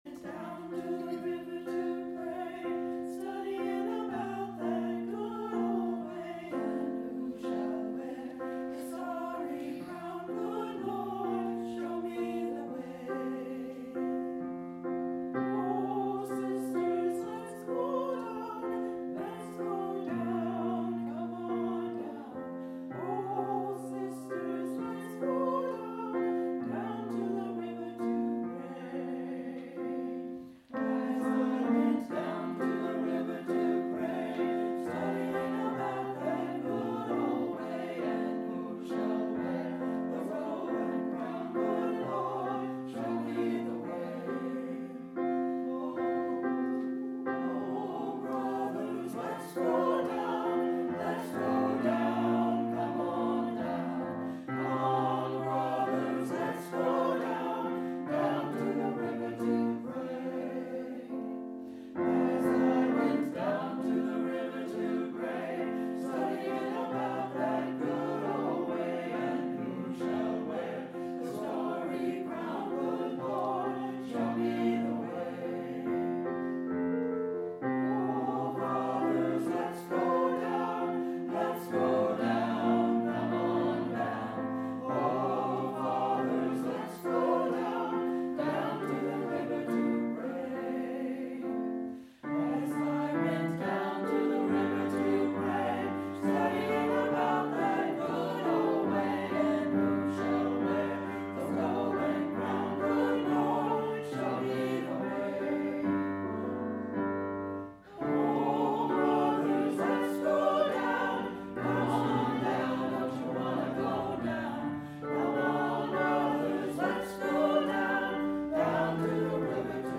[Sorry, did not record the OT reading]